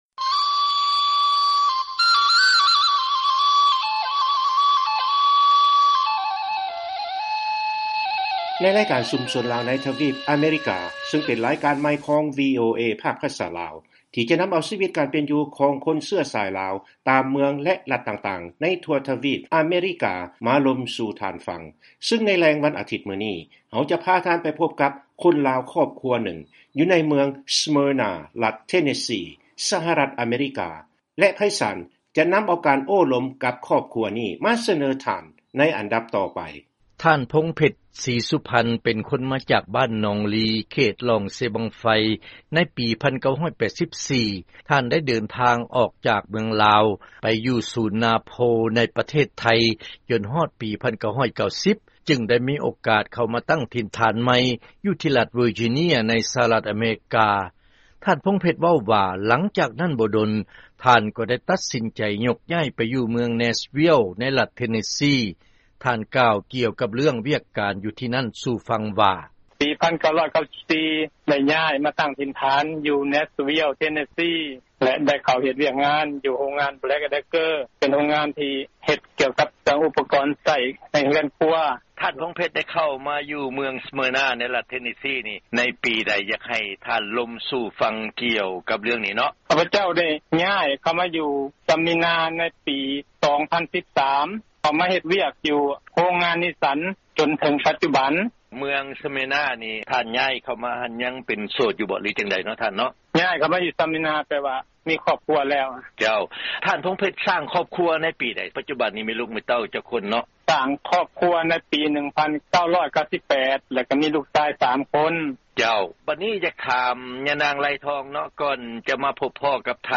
ເຊີນຟັງລາຍງານ ການໂອ້ລົມກັບຄົນລາວ ໃນເຂດເມືອງສເມີນາ ທີ່ວຽກໃຫ້ບໍລິສັດລົດນິສສັນ